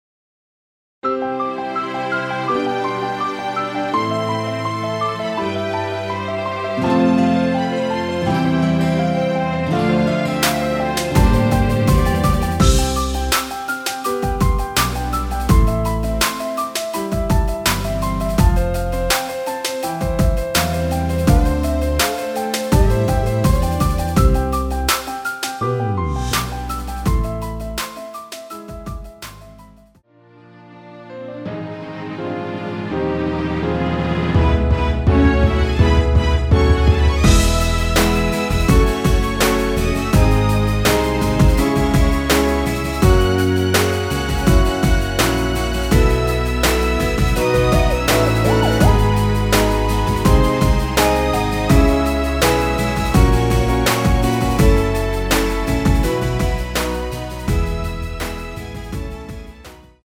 내린 MR
◈ 곡명 옆 (-1)은 반음 내림, (+1)은 반음 올림 입니다.
앞부분30초, 뒷부분30초씩 편집해서 올려 드리고 있습니다.
중간에 음이 끈어지고 다시 나오는 이유는